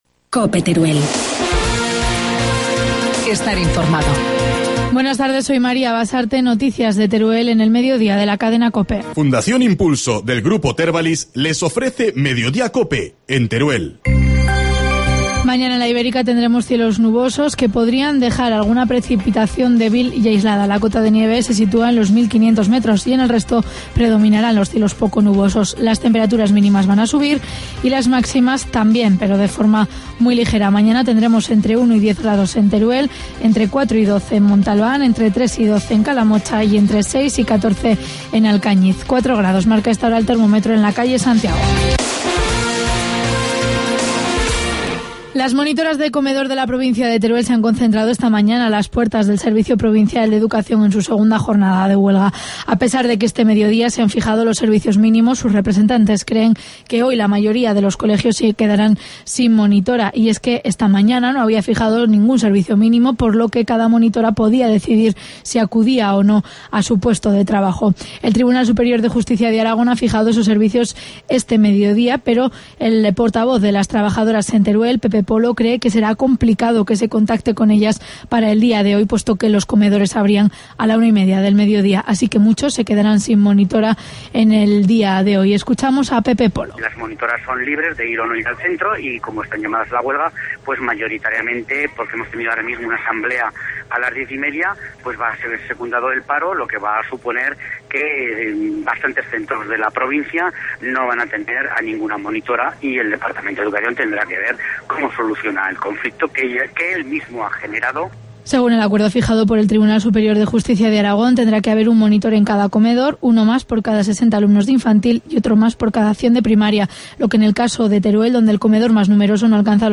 Informativo mediodía, miércoles 9 de enero